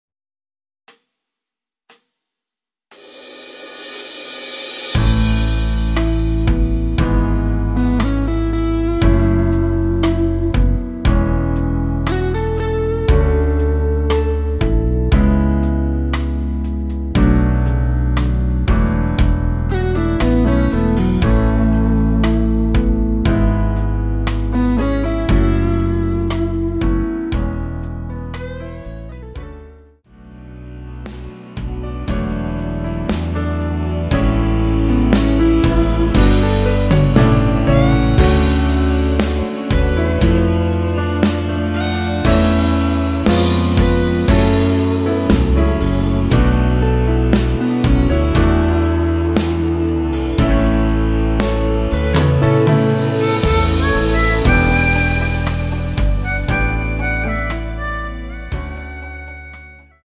전주없이 시작하는 곡이라 카운트 4박 넣어 놓았습니다.(미리듣기 참조)
앞부분30초, 뒷부분30초씩 편집해서 올려 드리고 있습니다.
중간에 음이 끈어지고 다시 나오는 이유는